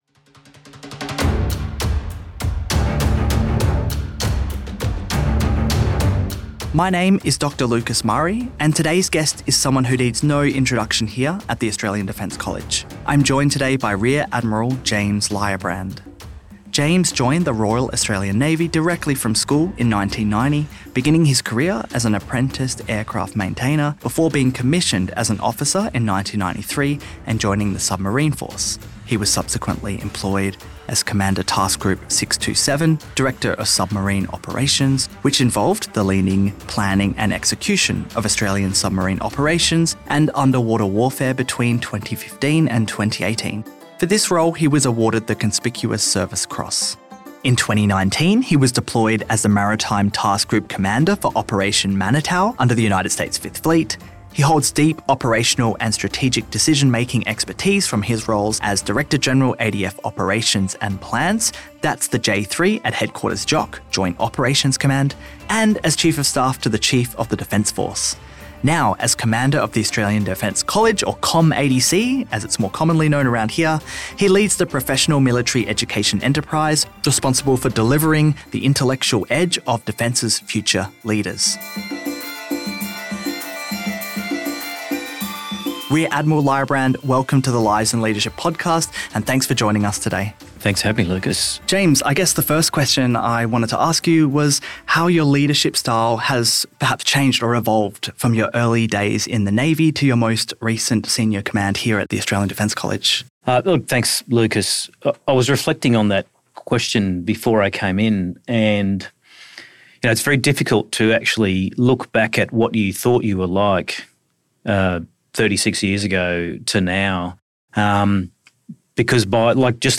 speaks with Rear Admiral James Lybrand, Commander of the Australian Defence College, whose naval career spans from the submarine force to senior strategic leadership roles.